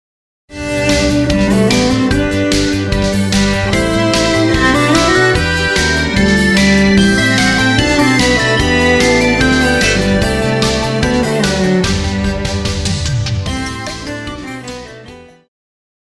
Classical
Cello
Band
Piano
Solo with accompaniment